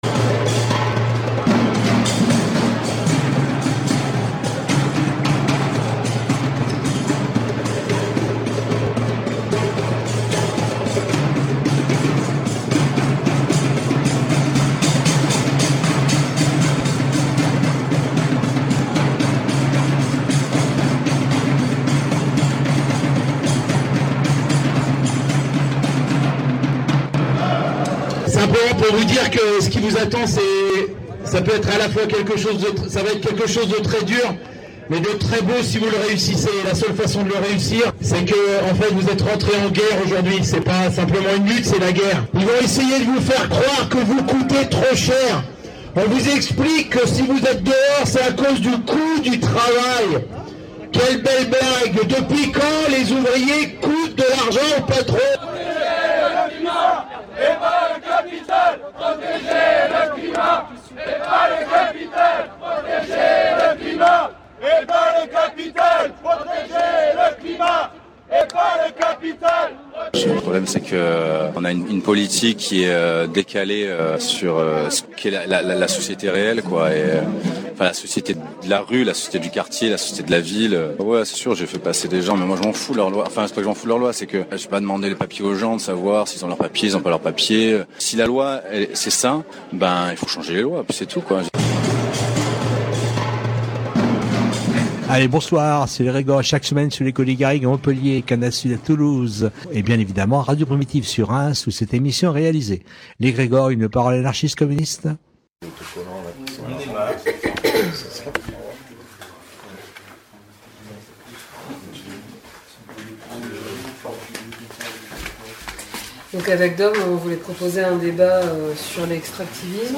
Ce soir, nous diffusons la 1ère partie du débat qui a eu lieu cet été aux Rencontres Libertaires du Quercy concernant l’extractivisme. En France, des collectifs se lèvent contre l’extraction des matières considérées comme essentielles pour la transition écologique.